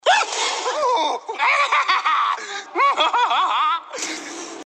Звуки Пеннивайза
На этой странице собрана коллекция пугающих звуков Пеннивайза из культового фильма ужасов. Вы можете слушать онлайн или скачать знаменитый зловещий смех, угрозы и другие аудиофрагменты в высоком качестве.